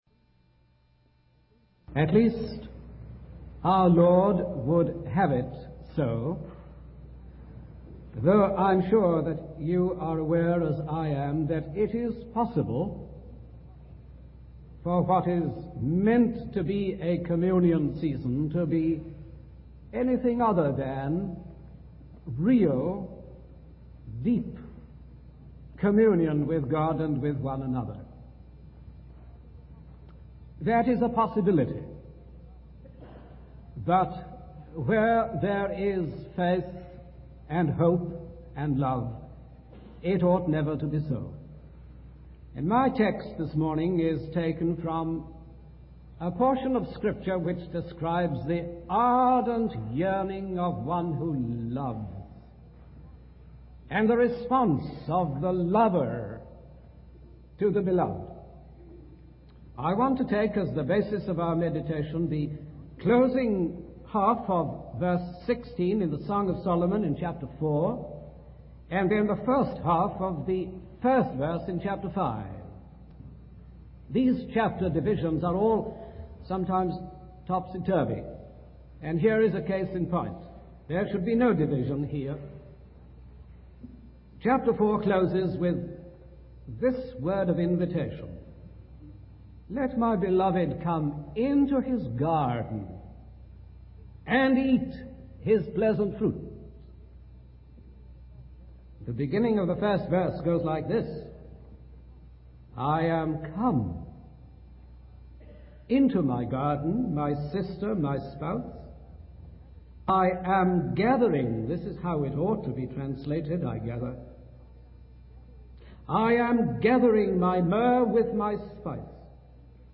In this sermon, the preacher focuses on the theme of communion with God and with one another.